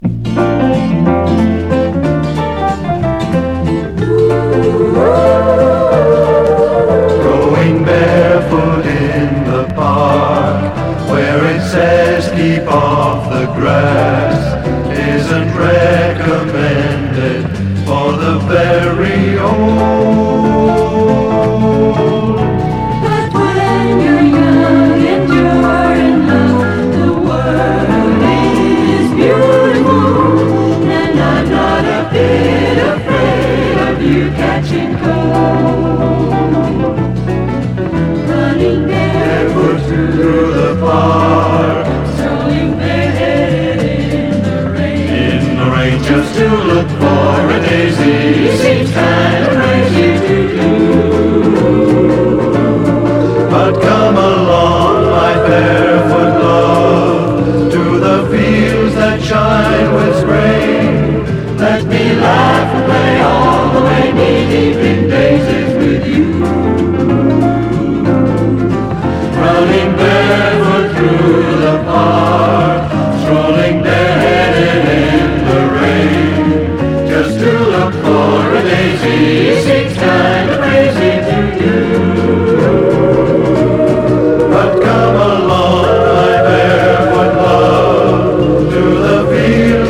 EASY LISTENING / VOCAL / FRENCH / CHANSON
スパニッシュ・ボッサ・ヴォーカル/イエイエ！